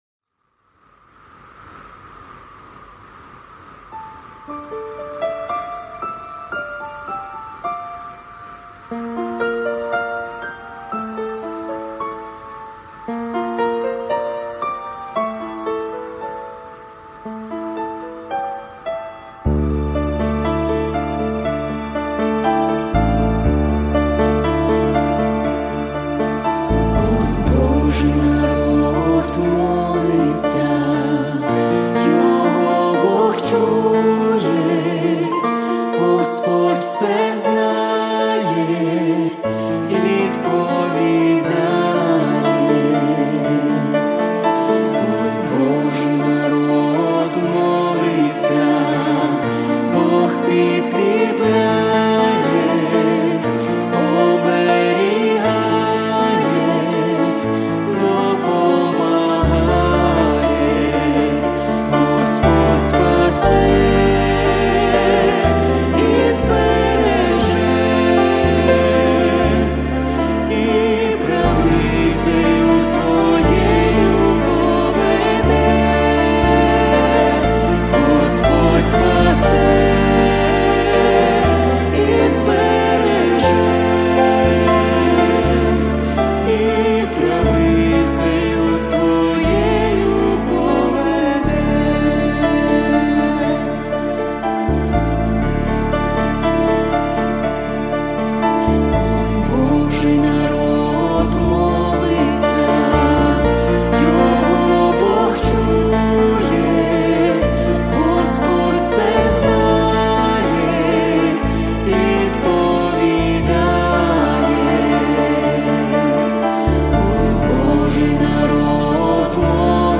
702 просмотра 217 прослушиваний 21 скачиваний BPM: 120